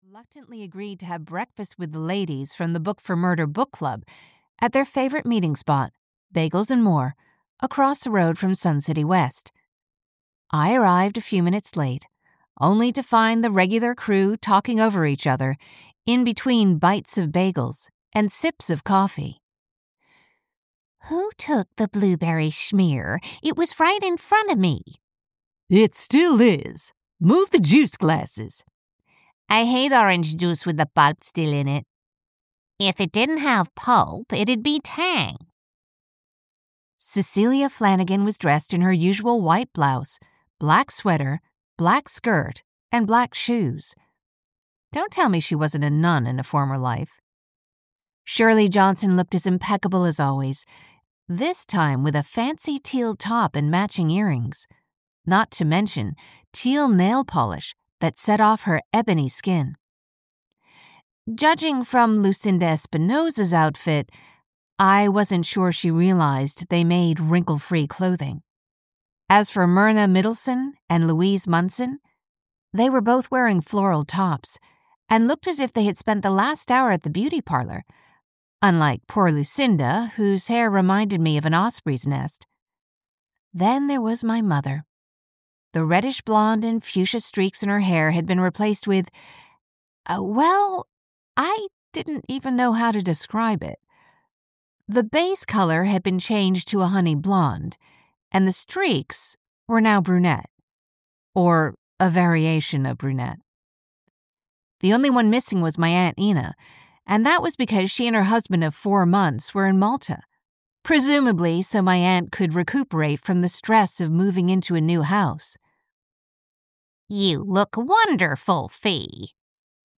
Staged for Murder (EN) audiokniha
Ukázka z knihy